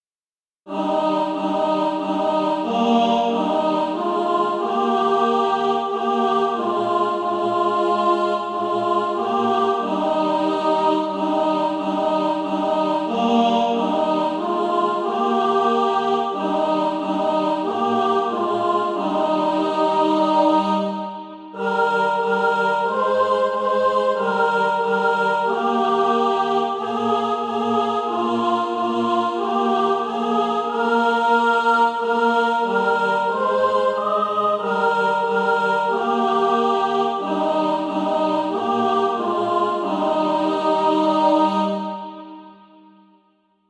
(SATB) Author
Tenor Track.
Practice then with the Chord quietly in the background.